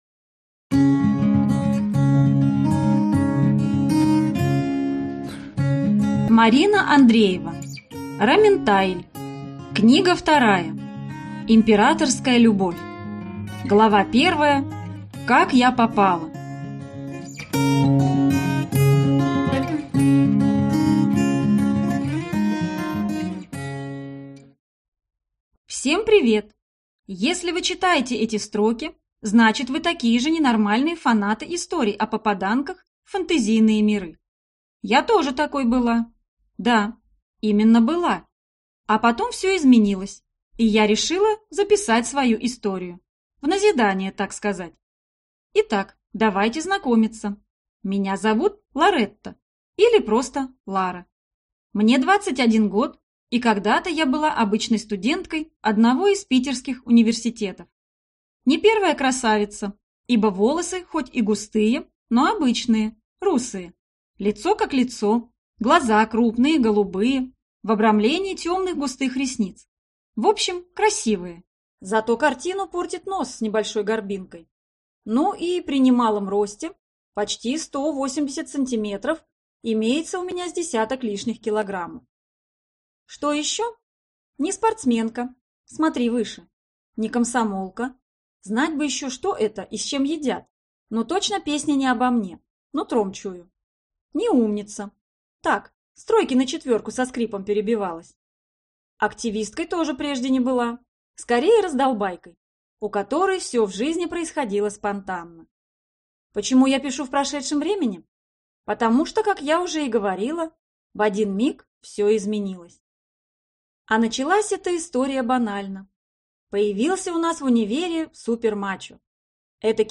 Аудиокнига Императорская любовь | Библиотека аудиокниг